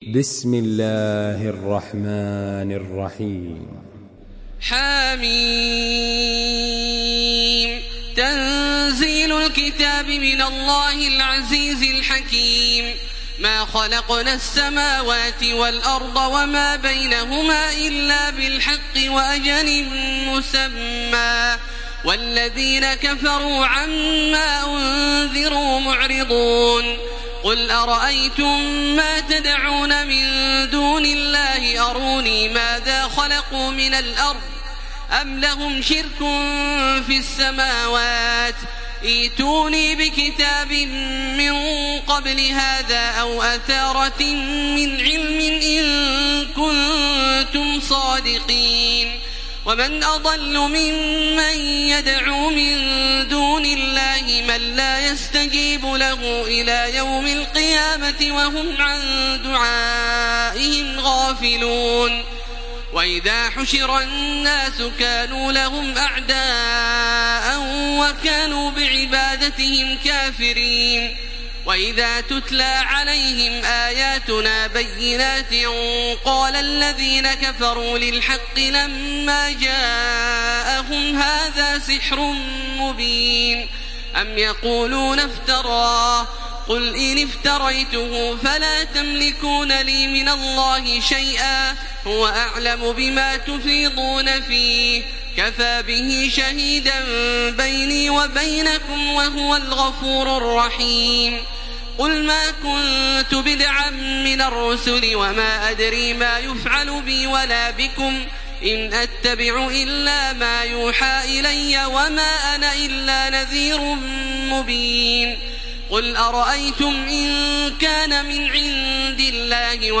Une récitation touchante et belle des versets coraniques par la narration Hafs An Asim.
Makkah Taraweeh 1434
Murattal